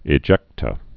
(ĭ-jĕktə)